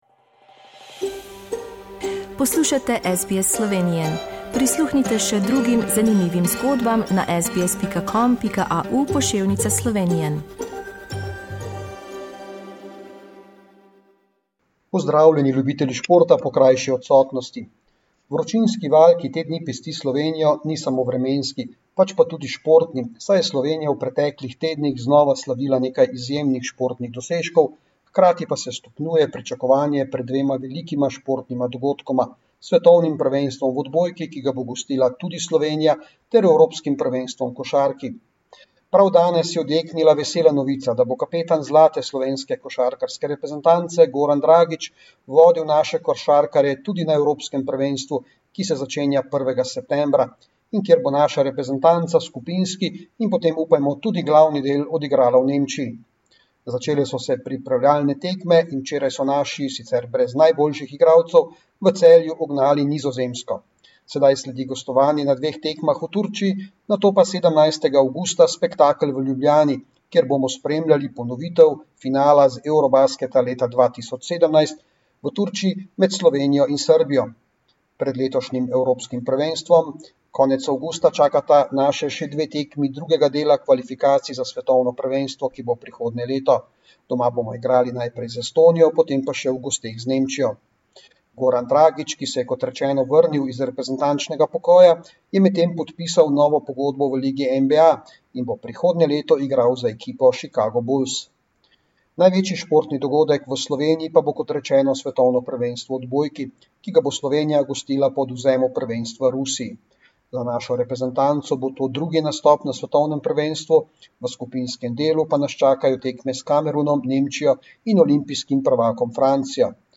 Listen to sports news from Slovenia.